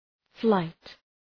Προφορά
{flaıt}